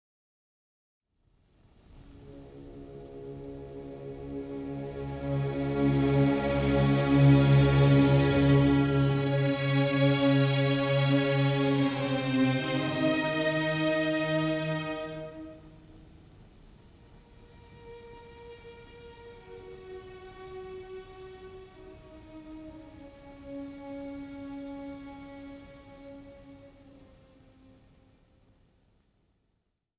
Opernszenen
Gewandhausorchester Leipzig, Gustav Brecher, Paul Schmitz
Der zweite Teil der CD-Serie enthält auf zwei CDs insgesamt 15 Ausschnitte von acht Opern in Aufnahmen von 1929 bis 1945.